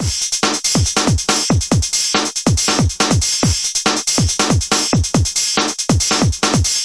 简单的Breakbeat 2
描述：简单的140bpm breakbeat鼓循环
Tag: 140-BPM 环路 drumloop